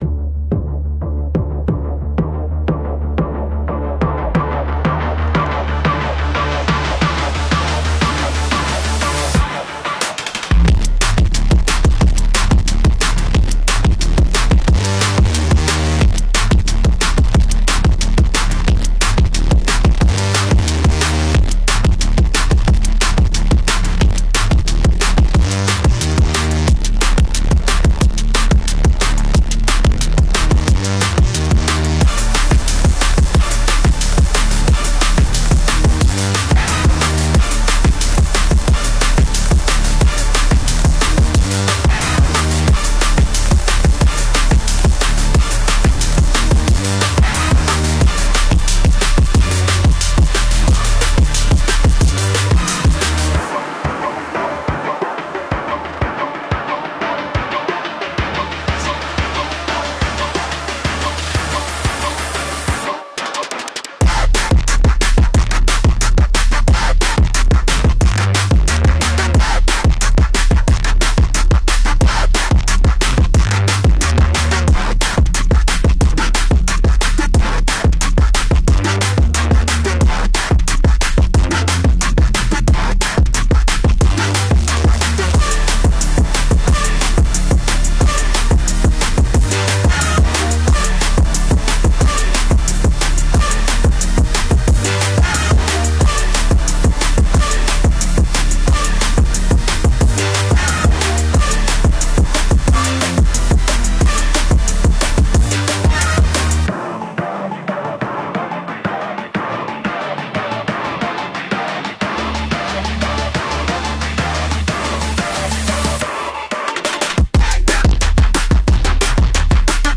Инструментальная музыка